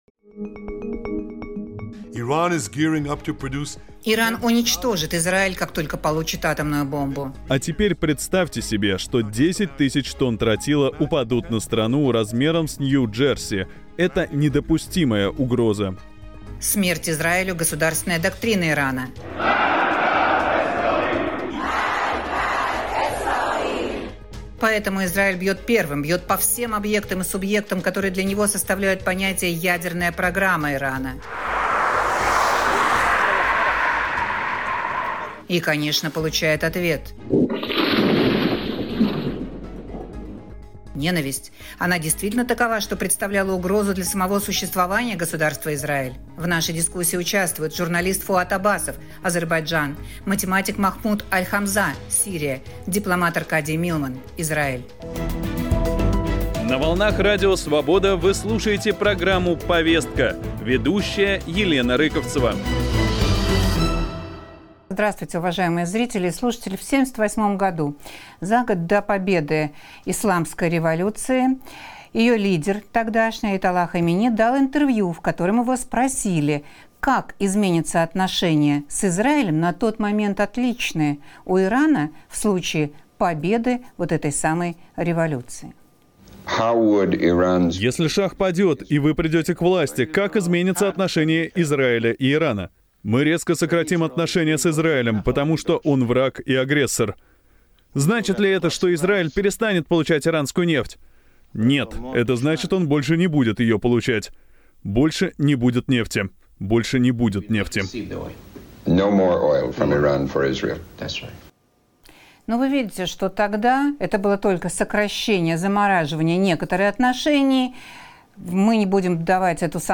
У Израиля был другой выбор? Полемика между экспертами из Сирии, Азербайджана и Израиля